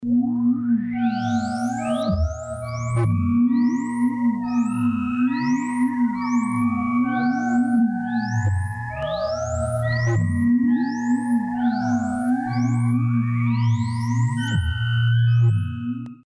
demo HEAR LFO modulator to VCF